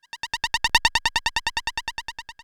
Machine11.wav